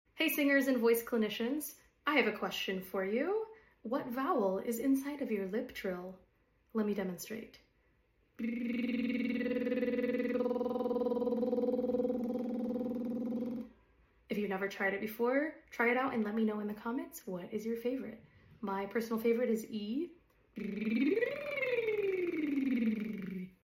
Obscure voice question of the day: what vowel is inside of your lip trill? By changing our tongue position, we can find a different resonance or a more comfortable execution of this exercise.